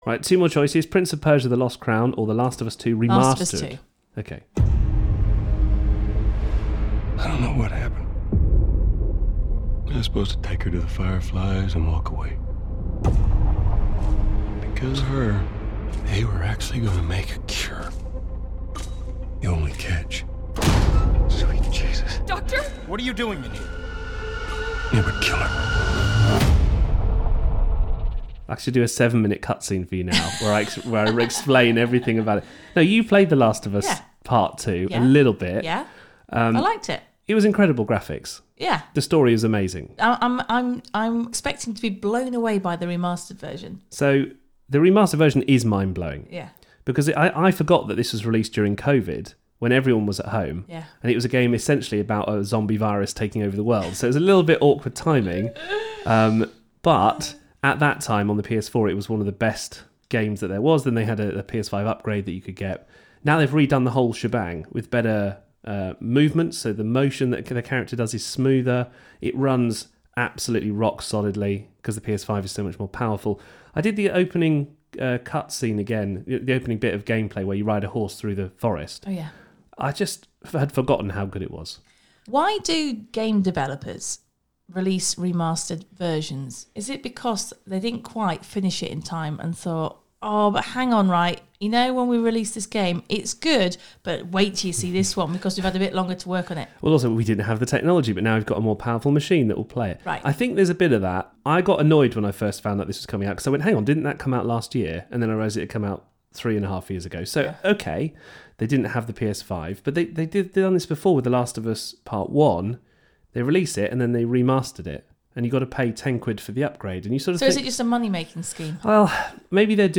Review: The Last of Us Part II Remastered